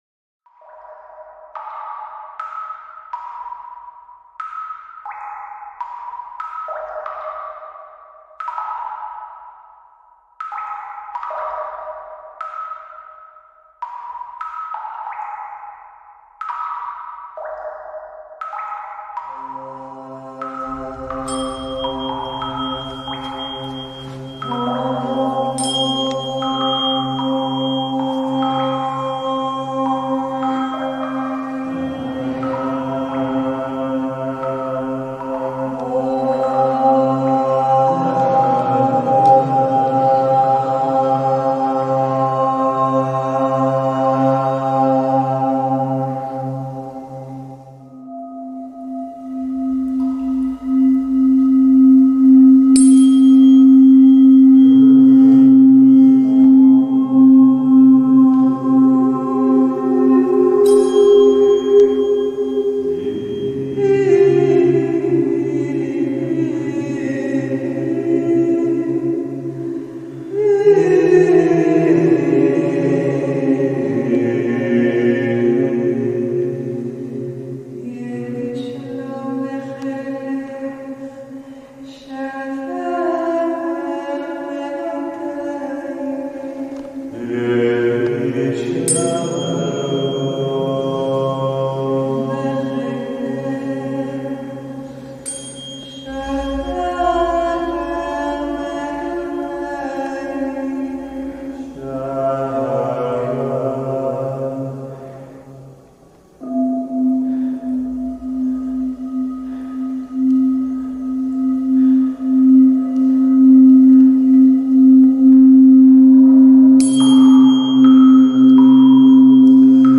528 Sound Healing in Jerusalem Blue Crystal Water Cave